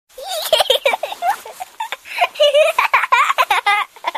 giggling-baby_20938.mp3